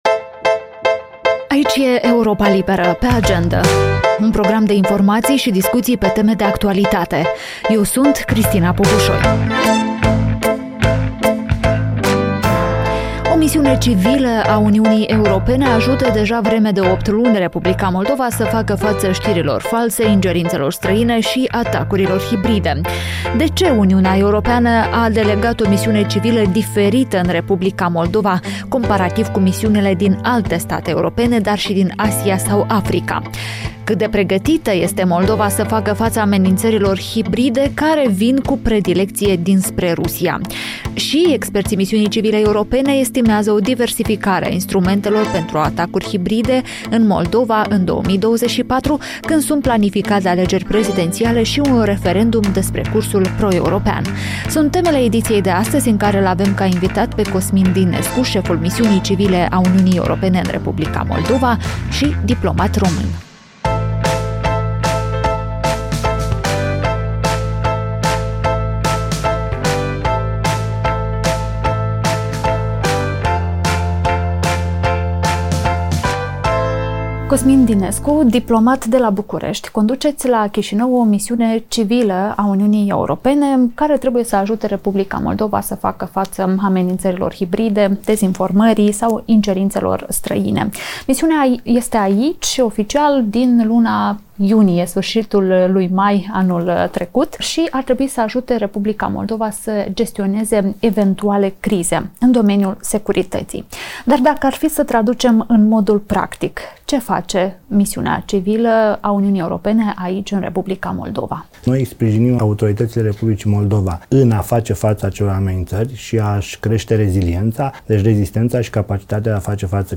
Șeful misiunii civile a UE în R. Moldova, Cosmin Dinescu vorbește în podcastul video al Europei Libere „Pe agendă” despre colaborarea cu SIS-ul și alte instituții responsabile de securitatea cibernetică, gestionarea crizelor și amenințărilor hibride.